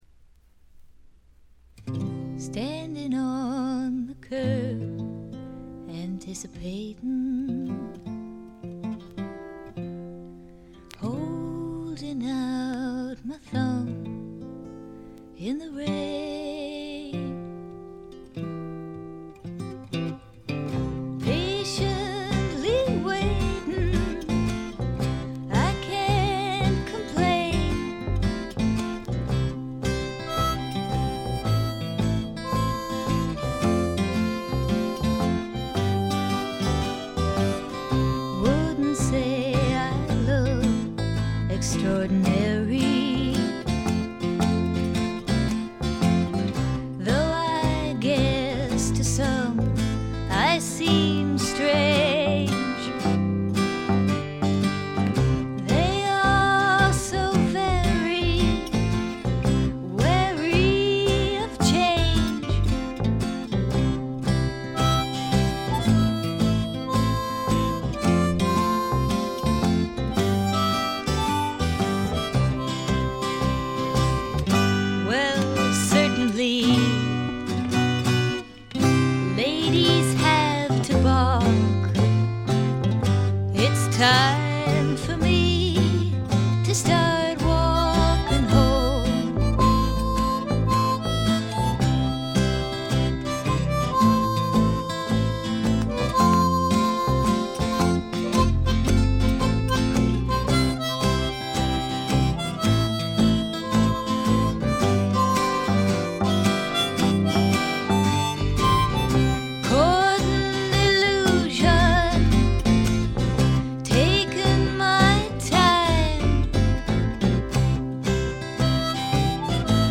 ほとんどノイズ感無し。
ルックスよりも少しロリ寄りの声で、ちょっとけだるくてダークでたまらない魅力をかもしだしています。
試聴曲は現品からの取り込み音源です。
Recorded at T.T.G. Studios, Hollywood